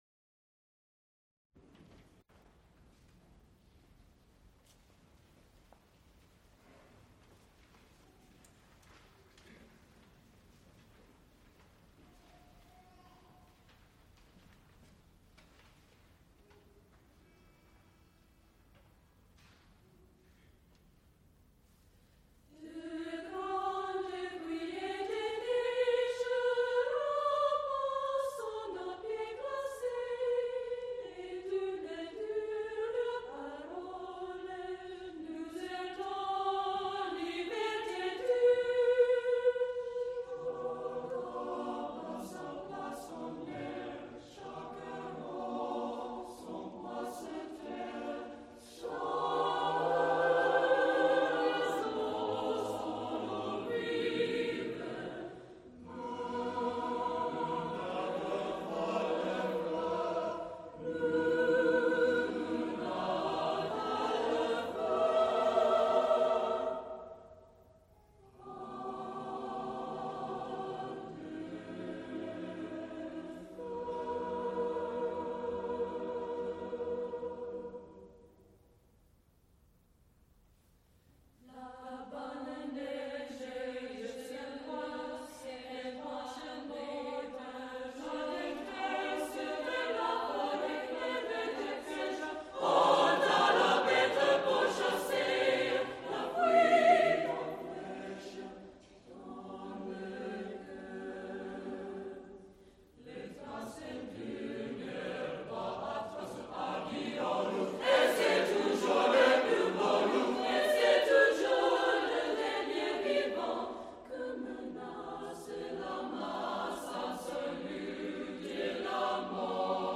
soprano
Recorded live April 8, 1979
Motets Madrigals, Italian Cantatas
Choruses, Secular (Mixed voices), Unaccompanied